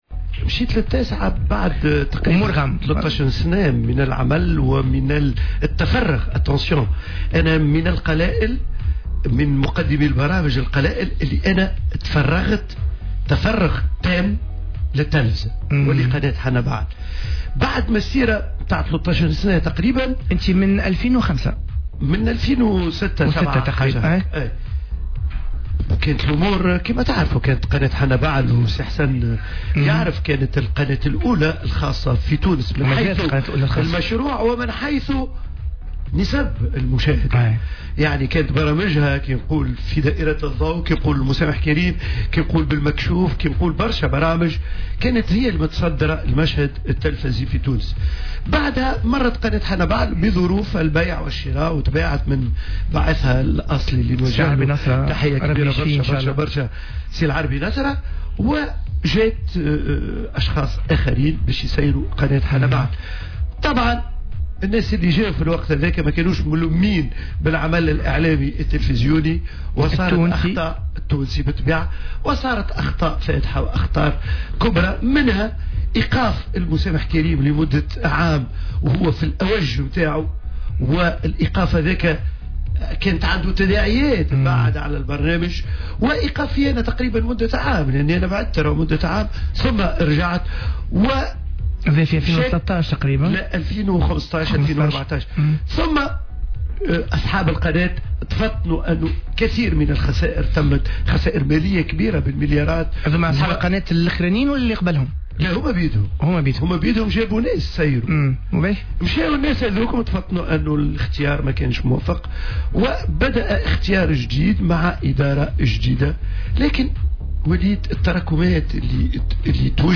حوار مع عبد الرزاق الشابي